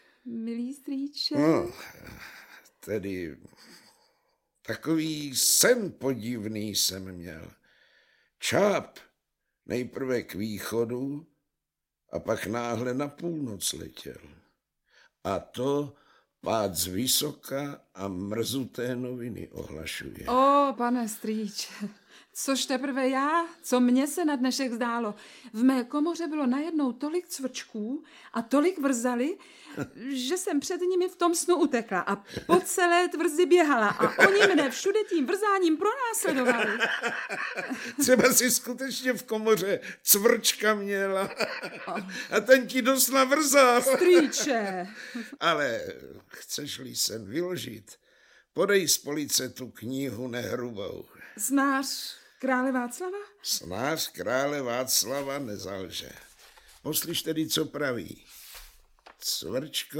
Audiobook
Read: Naďa Konvalinková